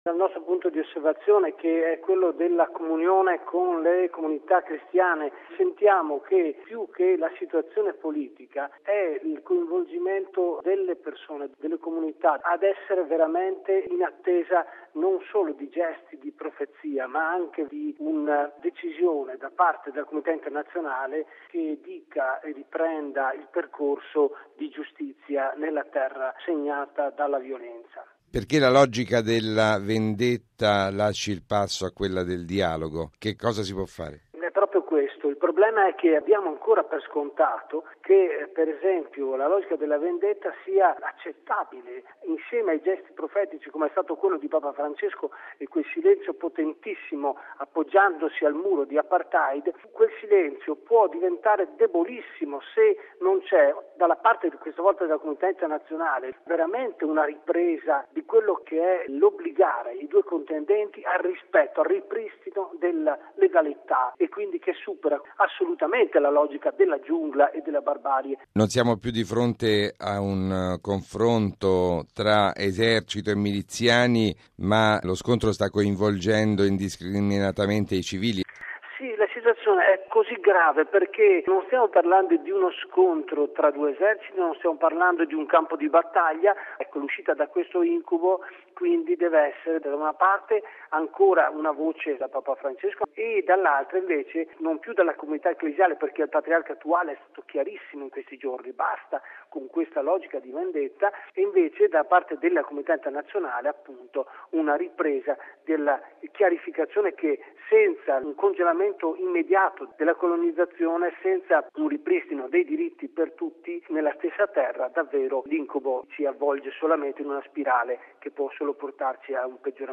di Pax Christi